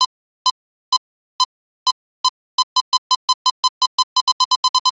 Звук таймера бомбы